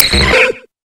Grito de Ditto.ogg
Grito_de_Ditto.ogg.mp3